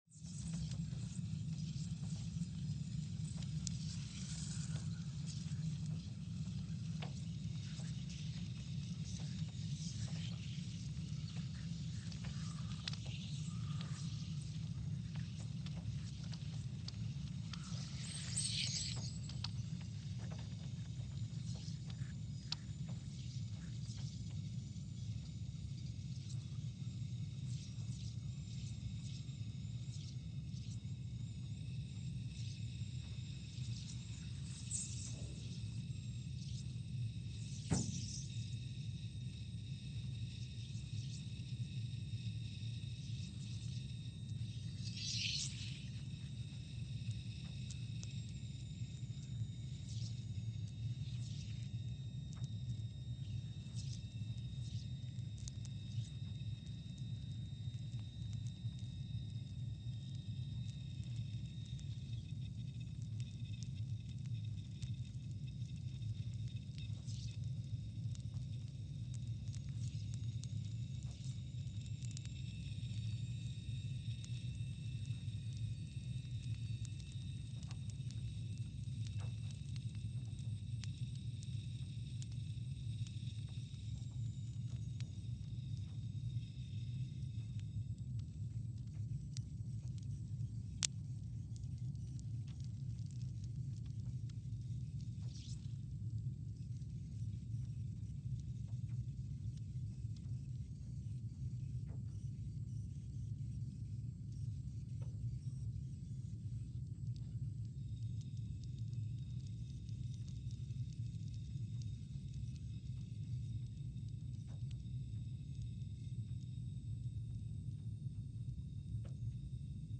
Scott Base, Antarctica (seismic) archived on November 19, 2020
No events.
Station : SBA (network: IRIS/USGS) at Scott Base, Antarctica
Speedup : ×500 (transposed up about 9 octaves)
Loop duration (audio) : 05:45 (stereo)